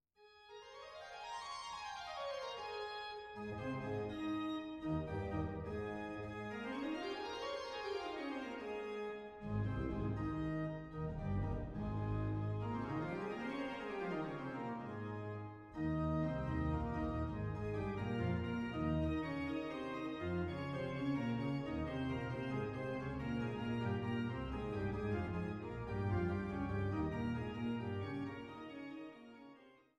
Trost-Orgel der Schlosskirche Altenburg
Naturtrompete